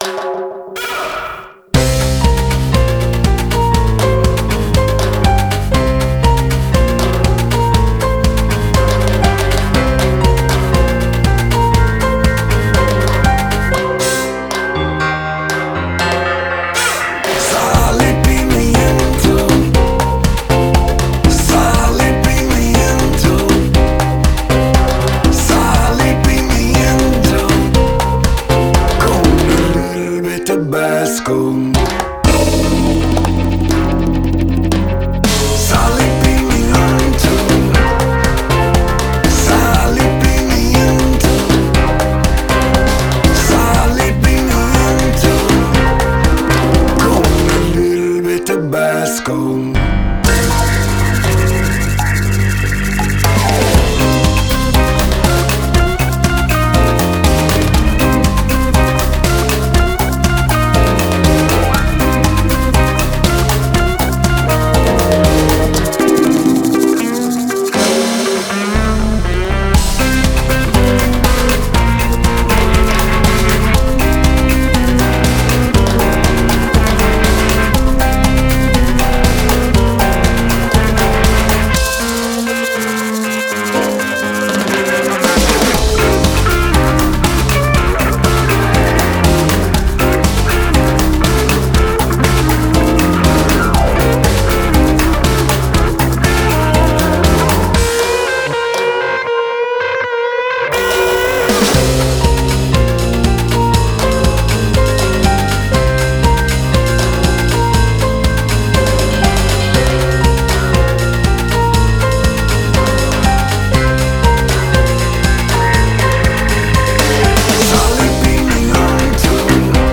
Жанр: Blues Rock